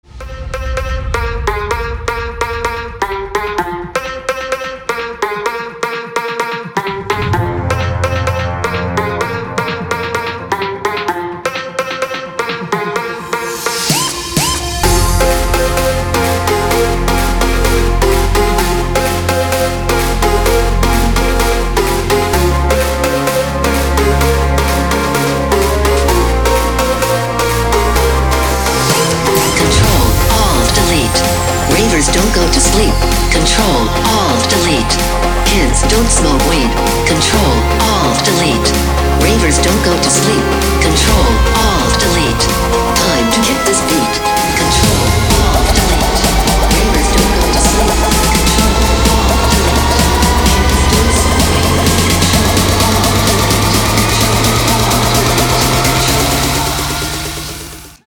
• Качество: 256, Stereo
громкие
dance
Electronic
EDM
электронная музыка
electro house